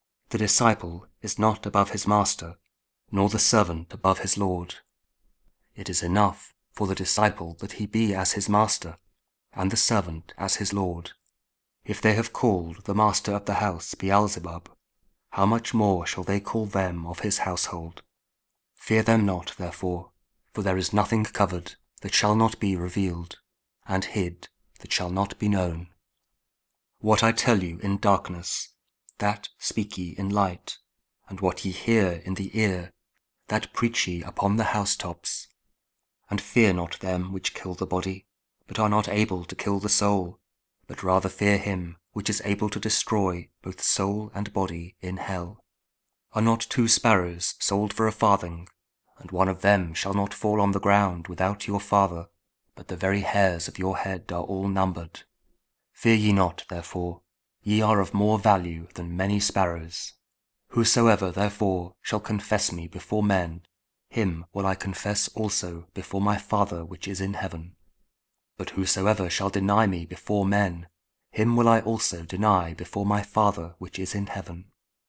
Matthew 10: 24-33 – Week 14 Ordinary Time, Saturday (King James Audio Bible KJV, Spoken Word)